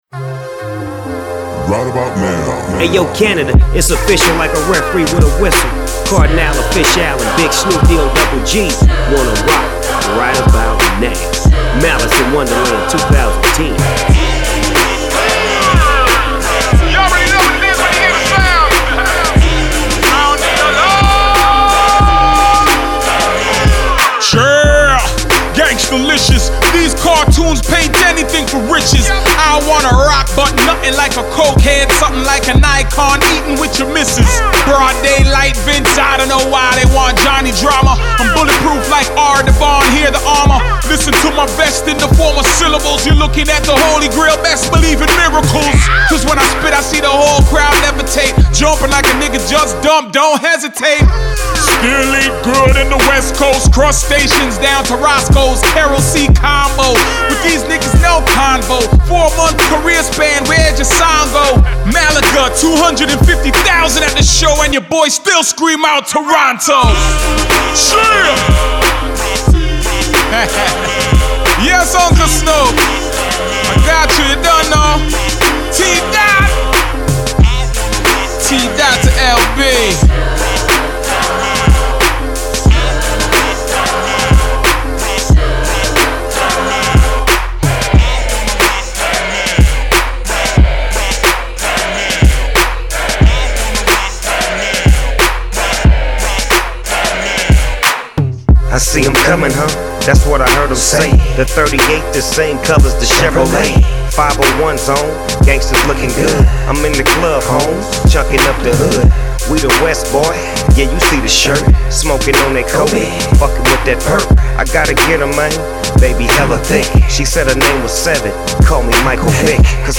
Hip Hop Music